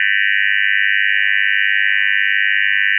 Сигнал